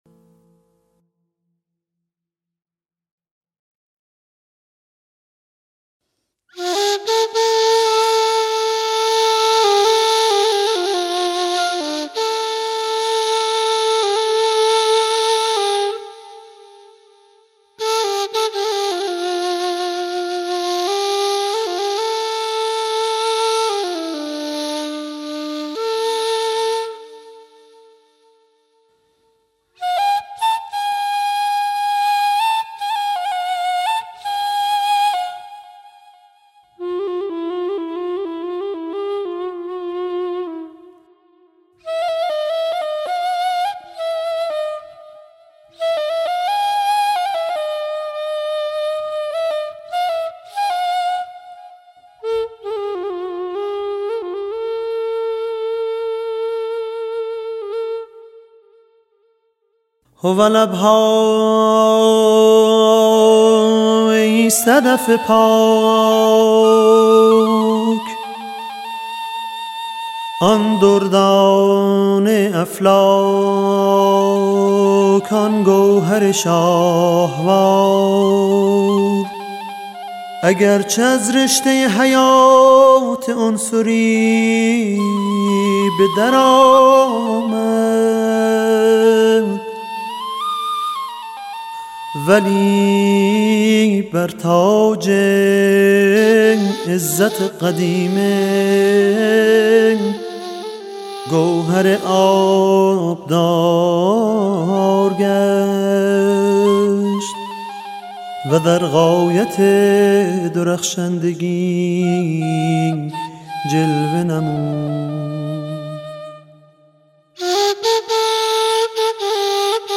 4. لوح مبارک حضرت عبدالبهاء ( دشتی )
قند پارسی لحن فارسی